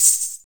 THIN TAMB L.wav